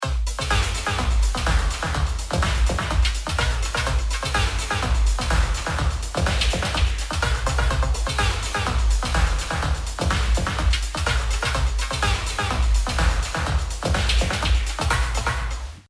次に立ち上がった状態のプリセットをそのまま流してみます。
ほほ～ぅ…Filter、Comb、RoomとDelayがかかってますが、このエフェクトもエグいな…。